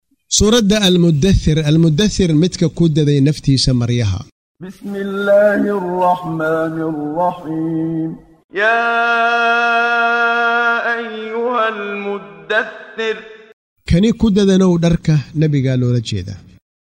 Waa Akhrin Codeed Af Soomaali ah ee Macaanida Suuradda Al- Muddaththir ( Dadane ) oo u kala Qaybsan Aayado ahaan ayna la Socoto Akhrinta Qaariga Sheekh Muxammad Siddiiq Al-Manshaawi.